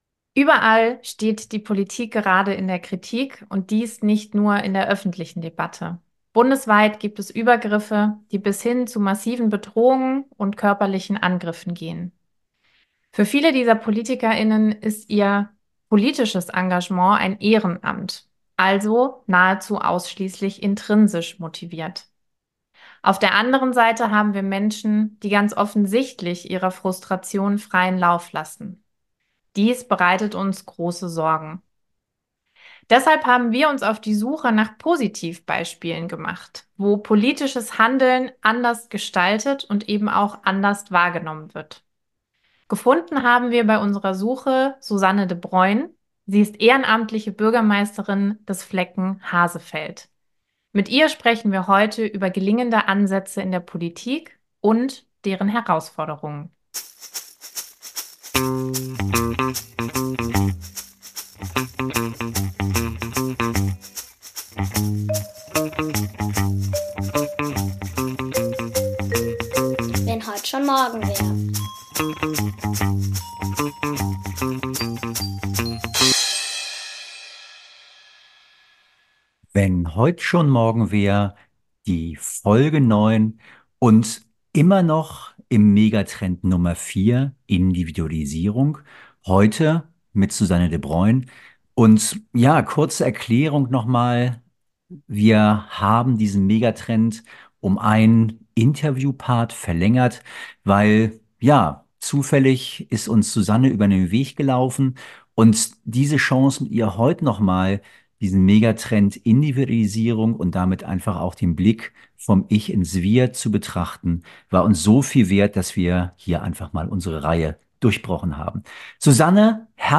Besondere Zeiten erfordern besondere Maßnahmen - deshalb widmen wir uns in einer zweiten Interviewfolge dem Megatrend Individualisierung und der Politik großer und kleiner Schritte.